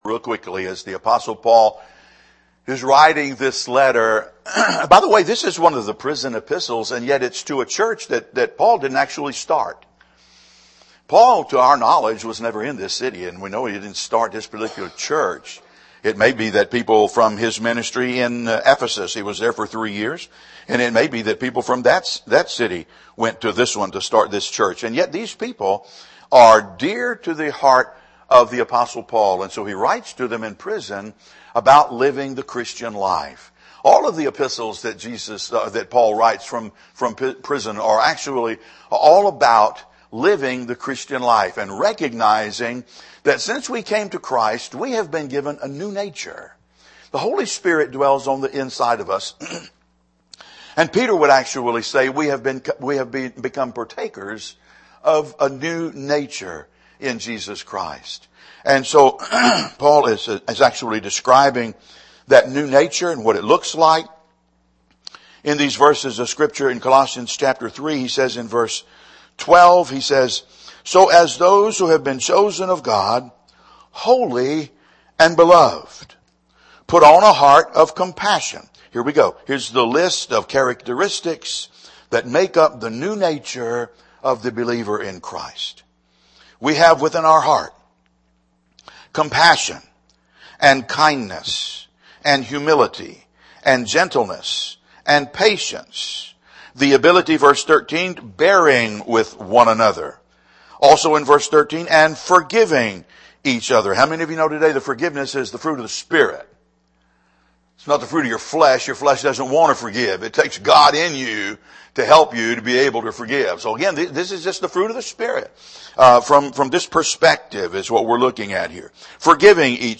In a Thanksgiving message entitled A Thankful Heart, we learn that thankfulness is a part of the believer's new nature and opens the door to the favor of God!